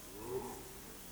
Animal Sounds
Mandrill 2717